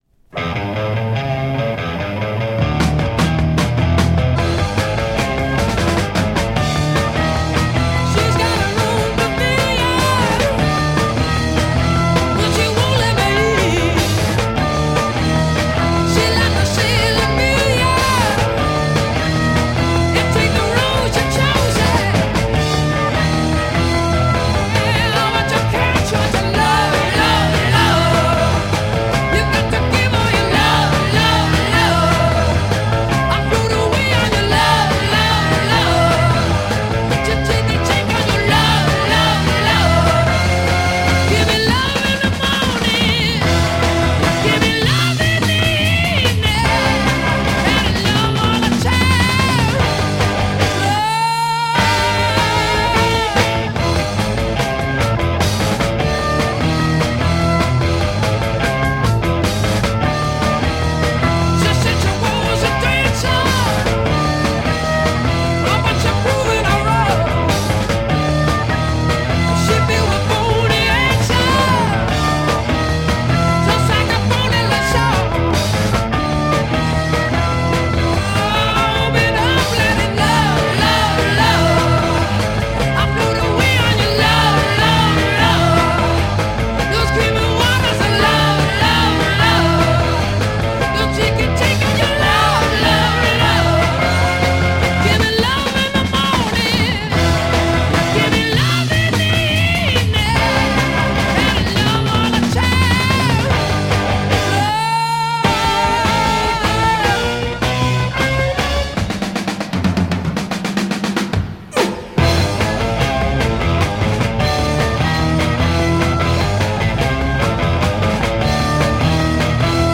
Hard rock psych kicker!
Heavy early hard rock US smashers!